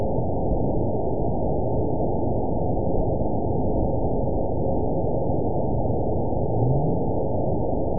event 917185 date 03/23/23 time 06:12:14 GMT (2 years, 1 month ago) score 9.62 location TSS-AB01 detected by nrw target species NRW annotations +NRW Spectrogram: Frequency (kHz) vs. Time (s) audio not available .wav